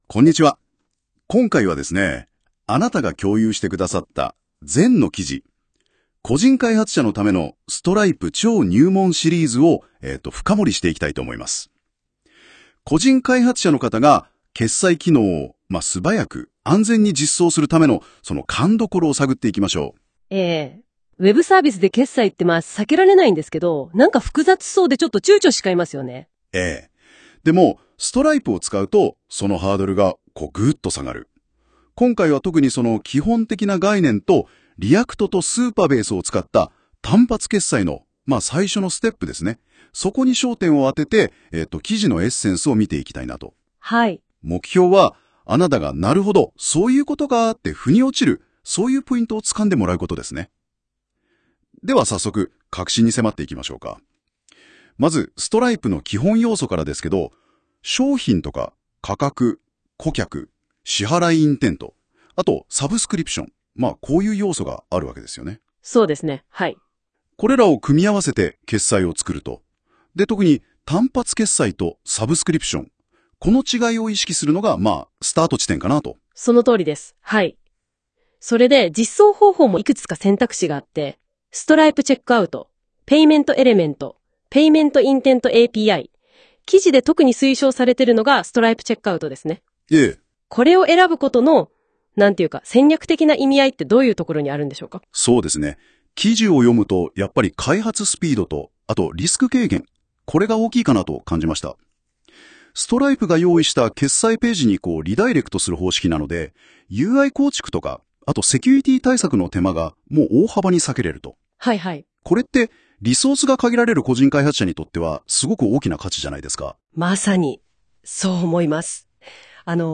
音声サマリ（NotebookLM製） ▶再生 第1章 Stripe決済の全体像 概要 Stripeを使うことで、従来は複雑だった決済システムの実装ハードルが大幅に下がります。